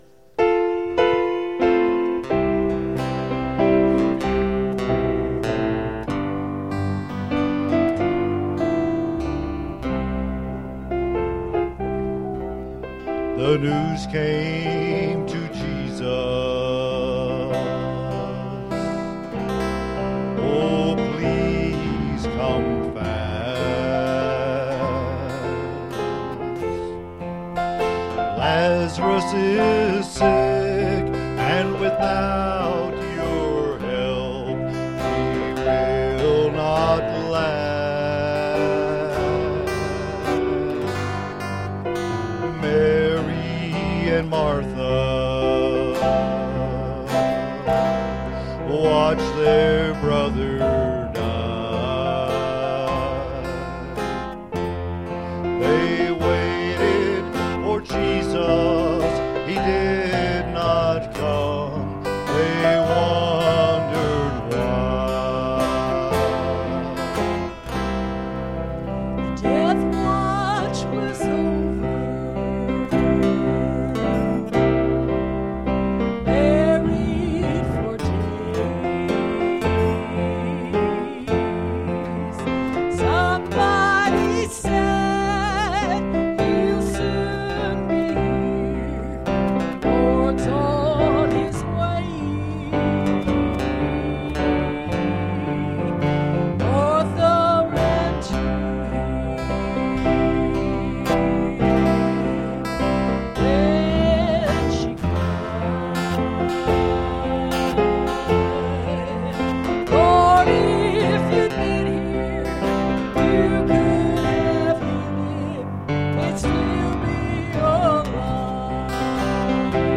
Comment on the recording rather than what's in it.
Series: Sunday Morning Services